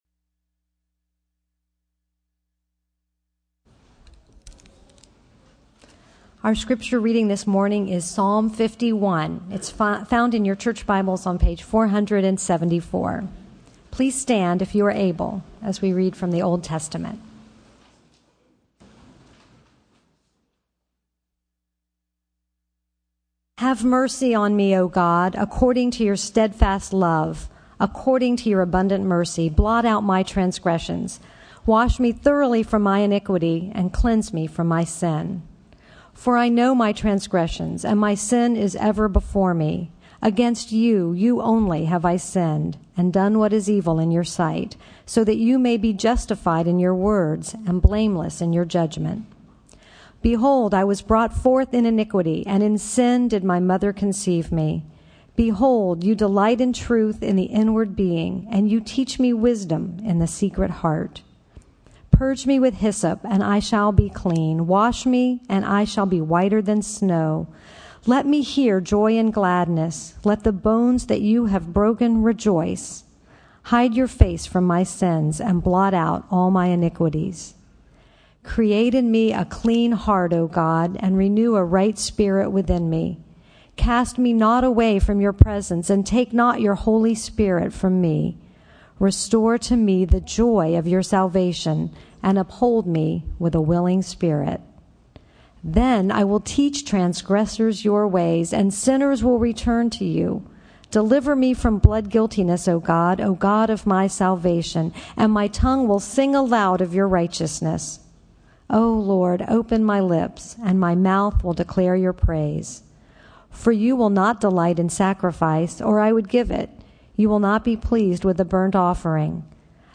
sermon-audio-8.25.13.mp3